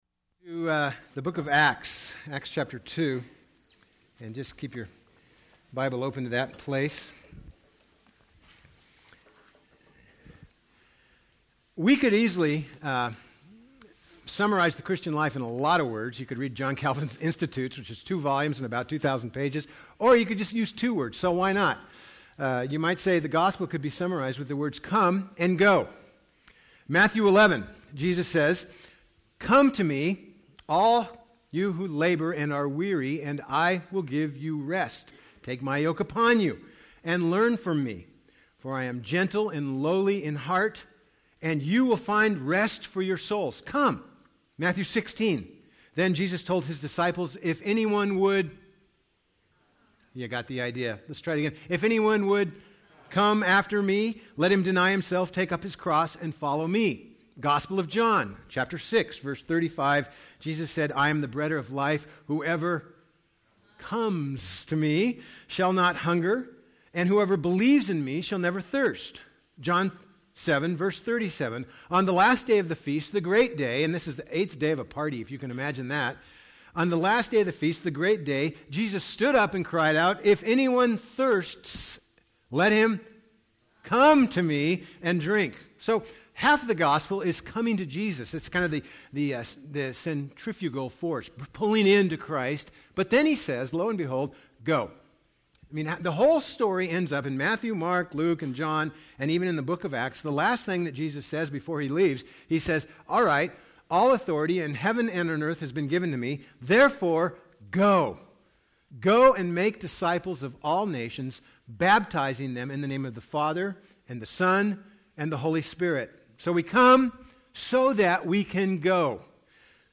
The Living Church Service Type: Sunday « Essentials